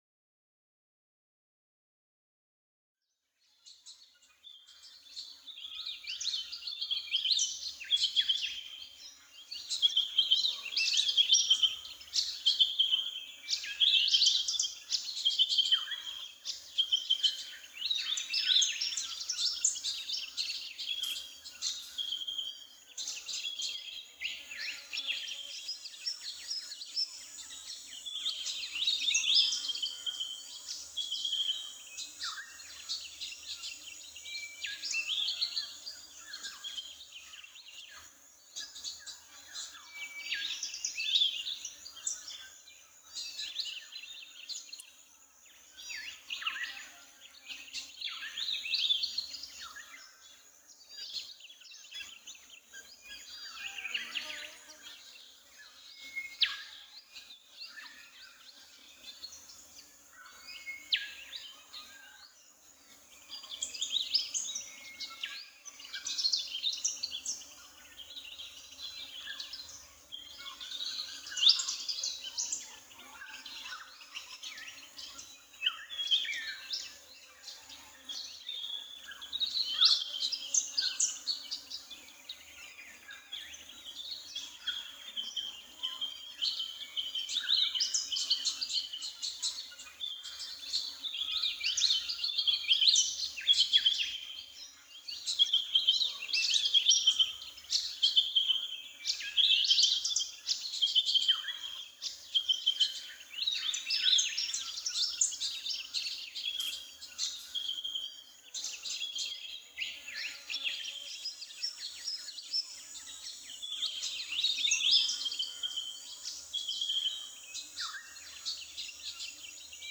AMB_Scene03_Ambience_RS.ogg